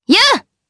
Lewsia_B-Vox_Attack3_jp.wav